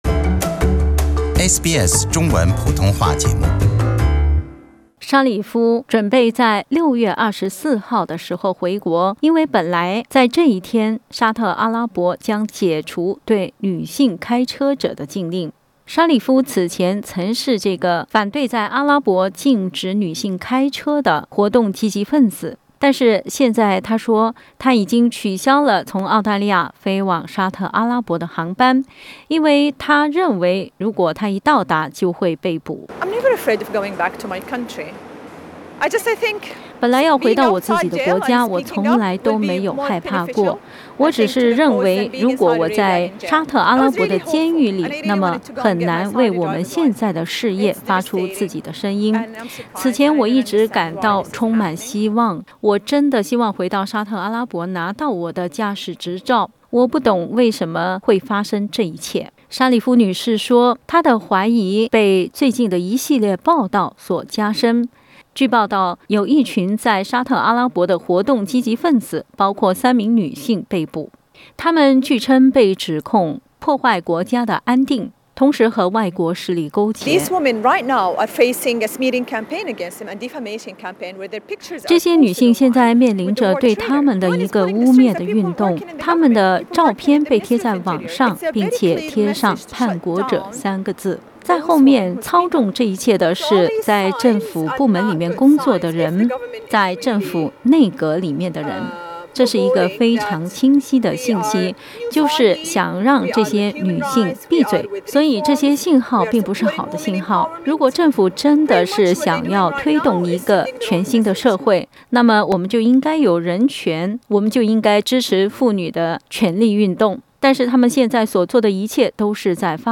Manal al-Sharif talks of death threats Source: SBS